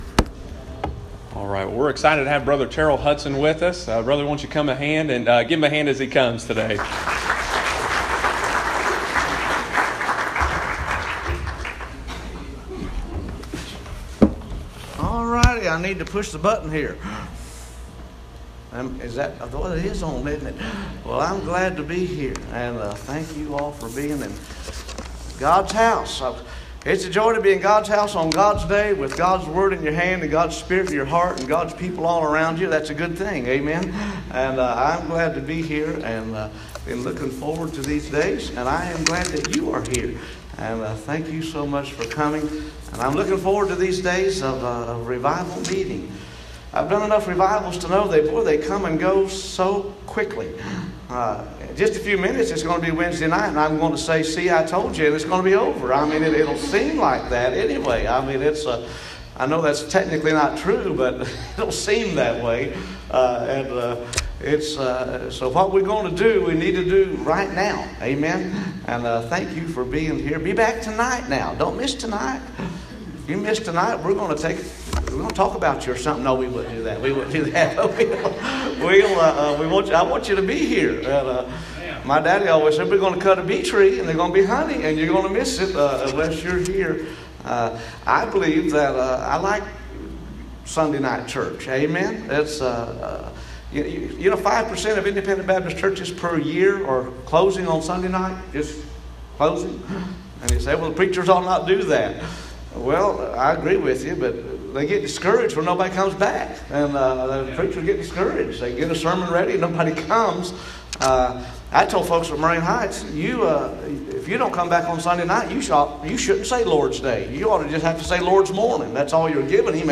preaches on Sunday morning, September 23, 2018.